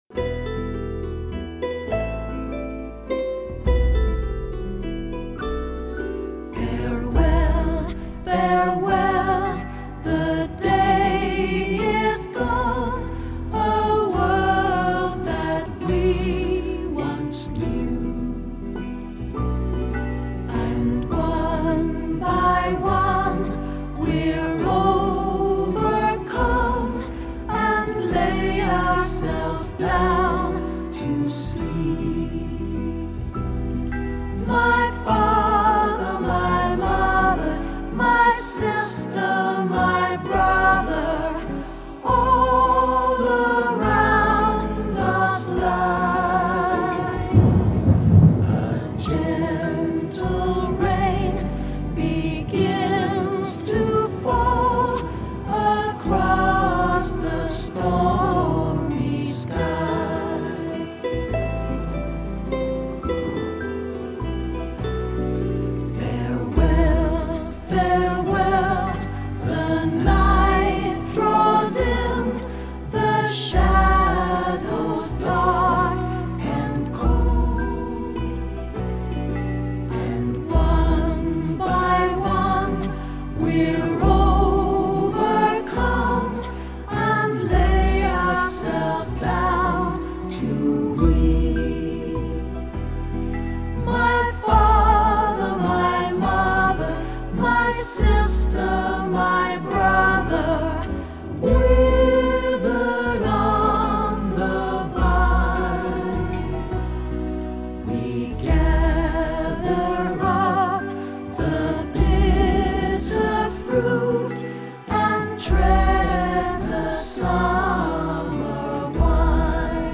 Song style: folk
smoothly and sadly
Full vocal